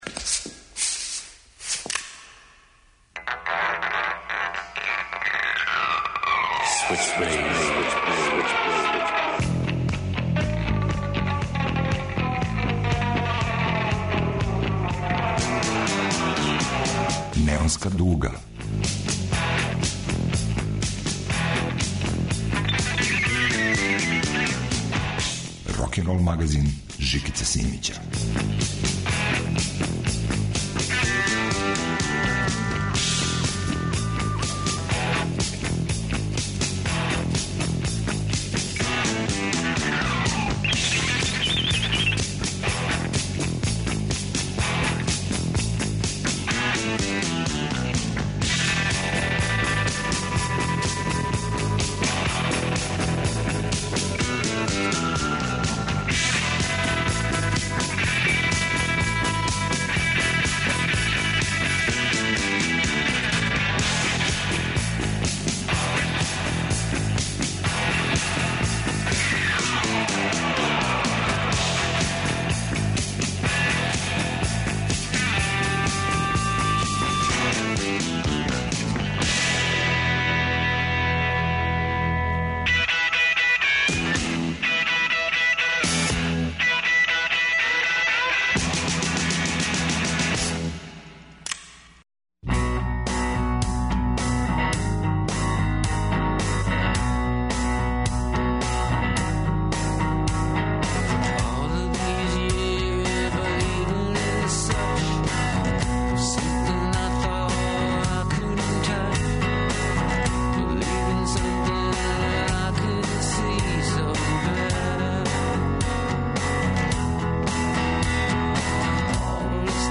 Концептуални кантри је на репертоару.
Рокенрол као музички скор за живот на дивљој страни.